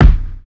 SouthSide Kick Edited (64).wav